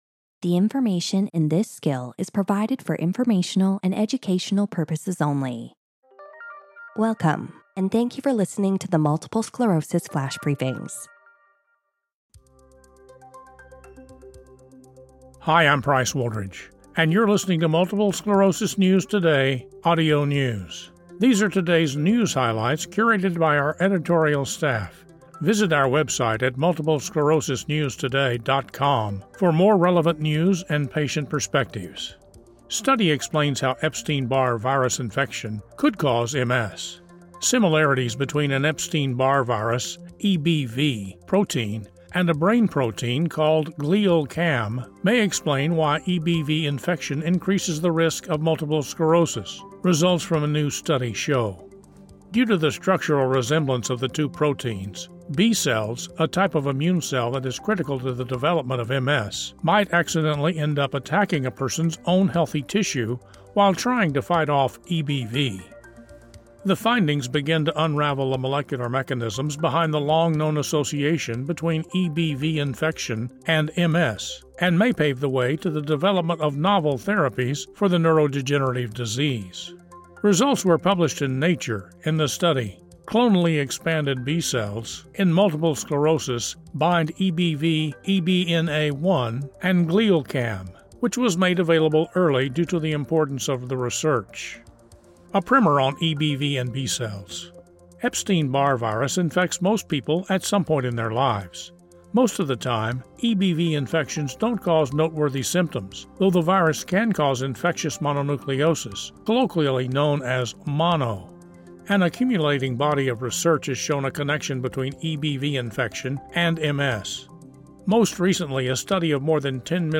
reads a news article about how the similarities between an Epstein-Barr (EBV) protein and GlialCAM protein may explain why EBV infection raises the risk of multiple sclerosis.